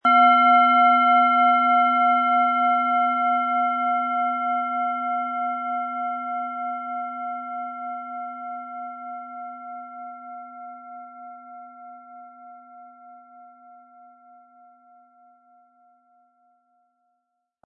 Planetenton 1
Mit einem Gewicht von 260-320 g entfaltet sie einen klaren, hellen Klang, der das Herz öffnet und das Gemüt aufhellt.
• Sanfter, heller Klang: Weckt Freude, stärkt Selbstvertrauen
PlanetentonSonne
MaterialBronze